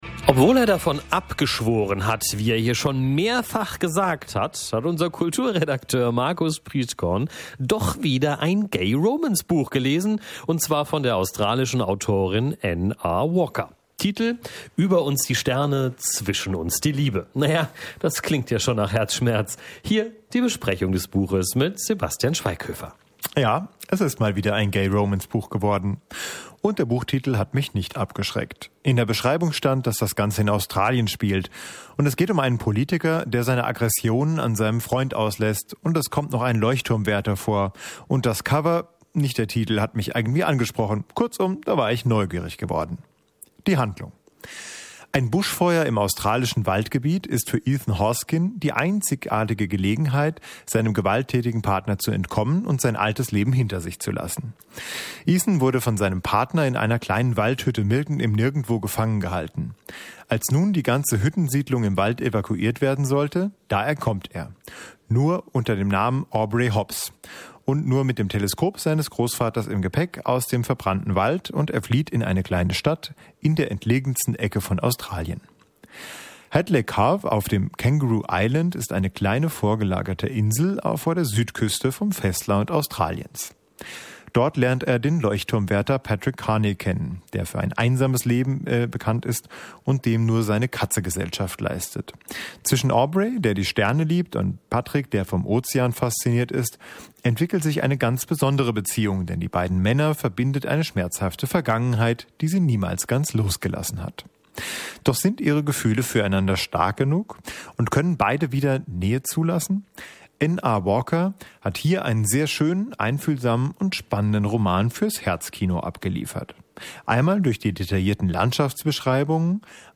Buchbesprechung – Über uns die Sterne, zwischen uns die Liebe